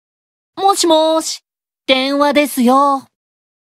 Notification Audio Files
Asmodeus_Call_Notification_(NB)_Voice.ogg.mp3